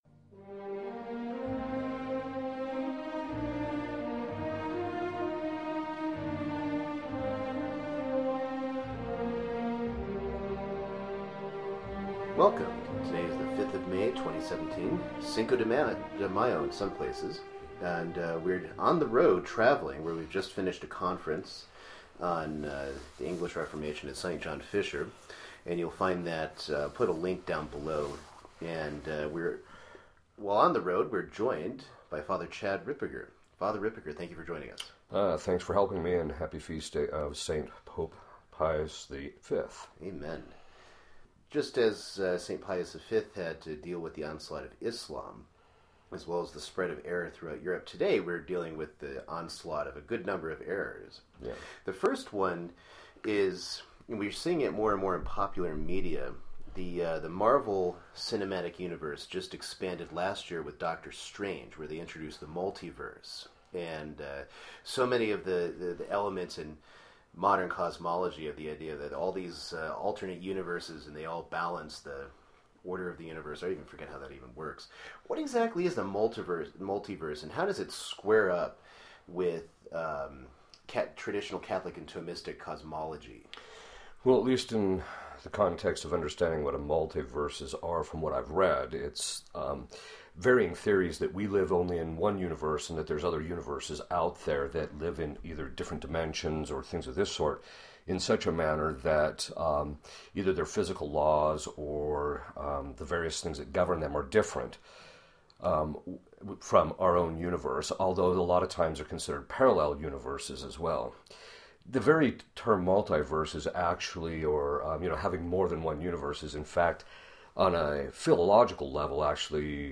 Interview 032